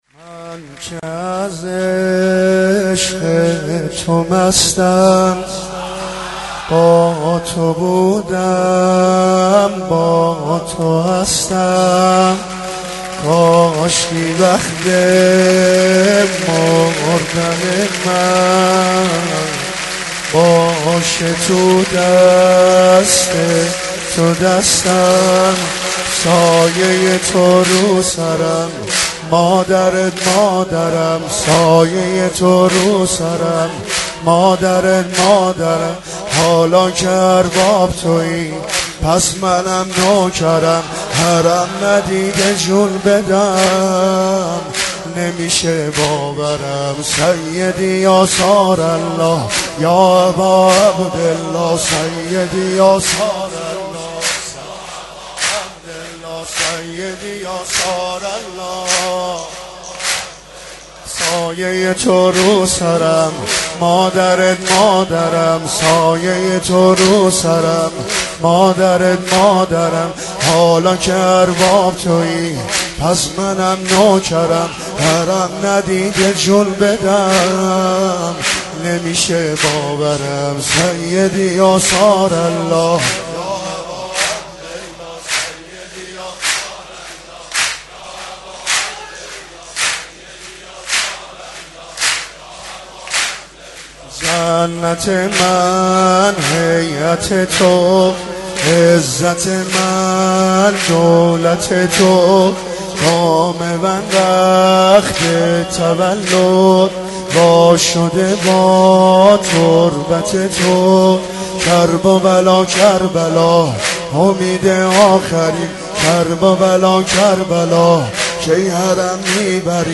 مولودی حاج عبدالرضا هلالی به مناسبت میلاد با سعادت امام حسین (ع)